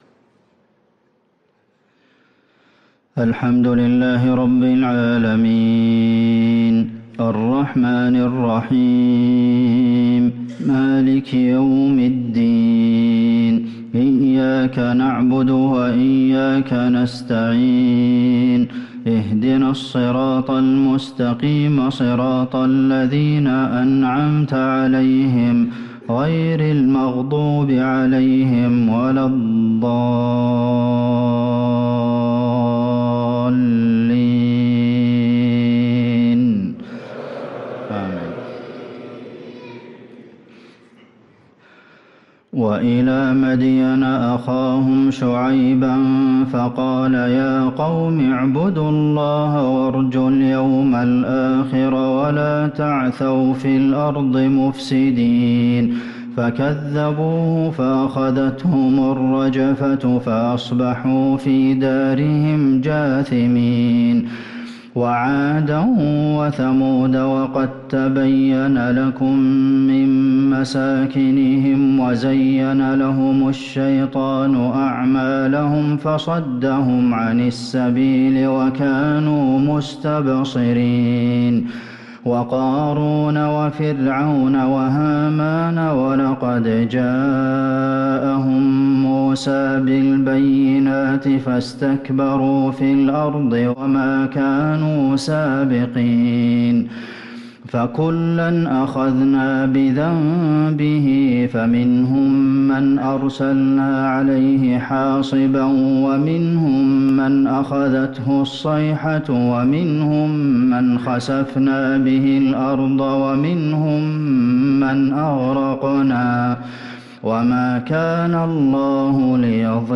صلاة العشاء للقارئ عبدالمحسن القاسم 11 جمادي الأول 1445 هـ
تِلَاوَات الْحَرَمَيْن .